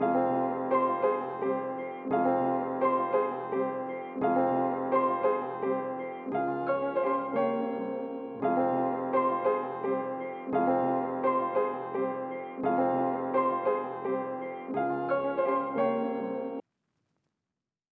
ks_alchemist 114bpm.wav